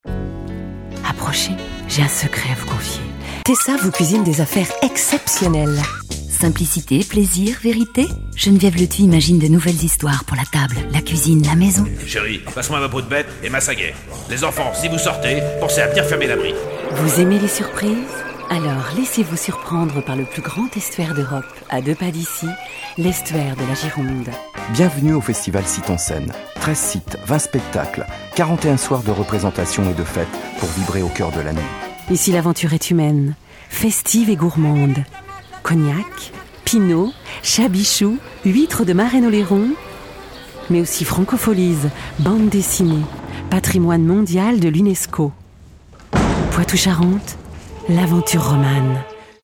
Extrait Pub ….